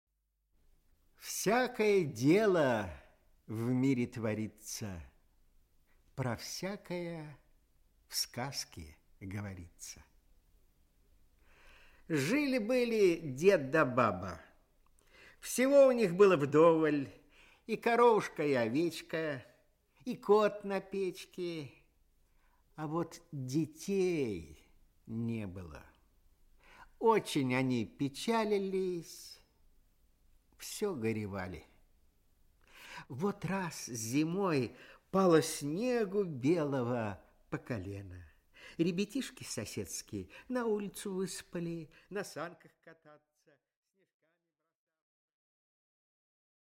Аудиокнига Снегурочка | Библиотека аудиокниг
Aудиокнига Снегурочка Автор Народное творчество Читает аудиокнигу Николай Литвинов.